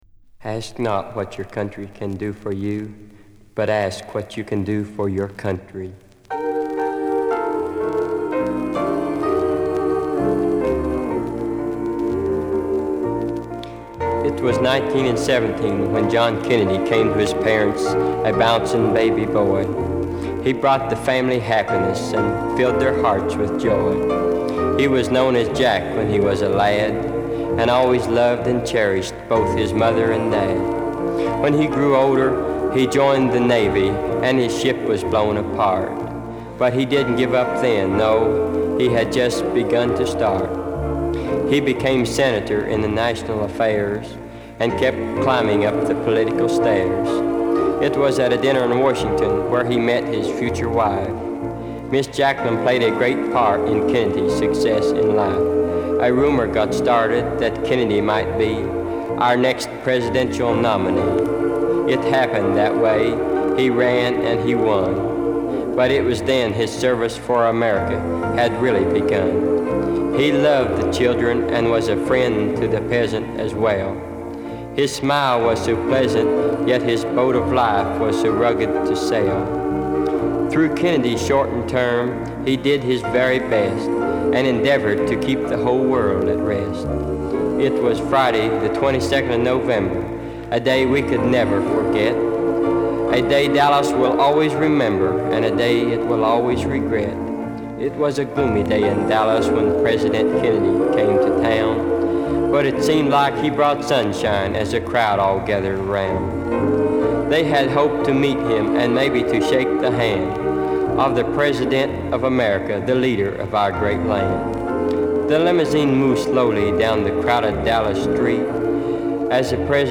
B面はJFKトリビュート・ノヴェルティを収めている。